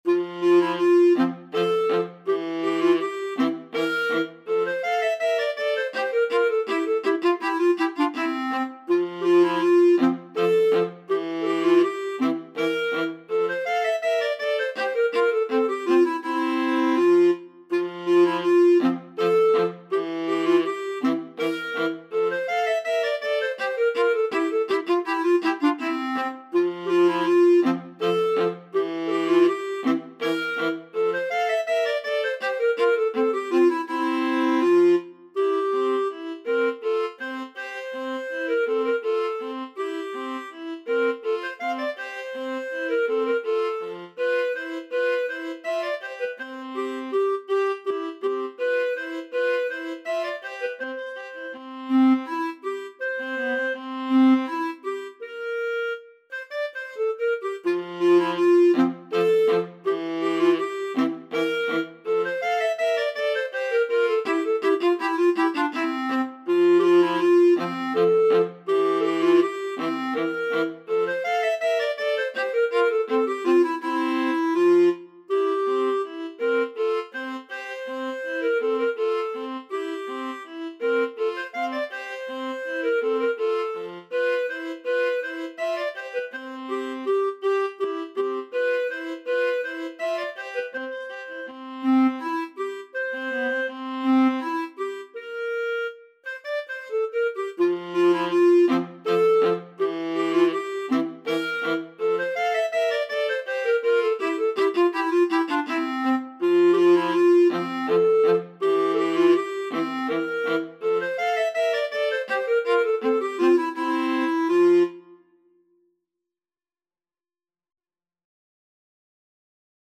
3/4 (View more 3/4 Music)
Classical (View more Classical Clarinet-Viola Duet Music)